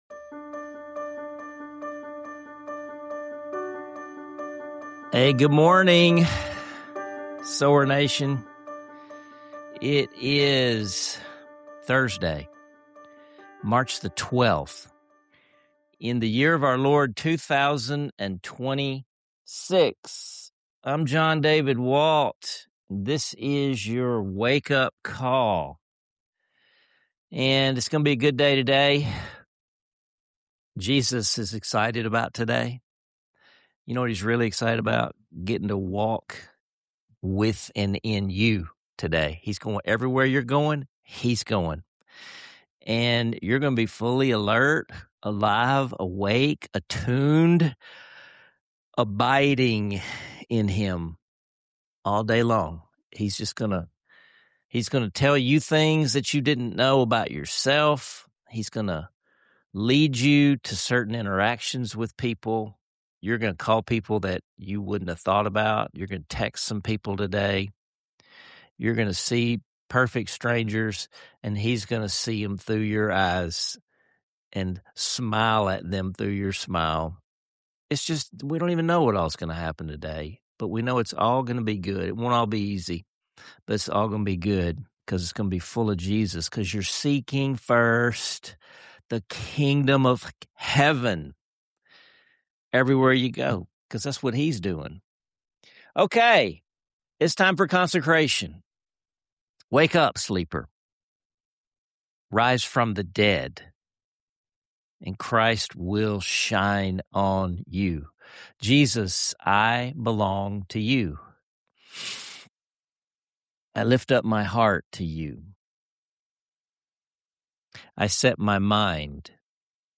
You’ll end this episode not only singing a classic hymn but also ready to carry God’s love and approval into every corner of your life.